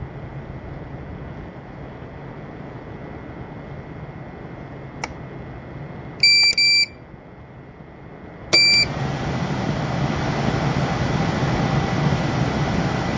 USV Lüfter Lautstärke …
Wir haben hier eine Tonaufnahme des originalen USV Lüfters erstellt, so dass man sich ein Bild bzw. einen Ton davon machen kann, wie sich der USV Lüfter im Idle und Load anhört. Der mittelfrequente Ton, der die Geräuschkulisse begleitet, schränkt den Einsatzzweck dieser USV etwas ein.
eaton_ellipse_pro_din_1200va_usv.mp3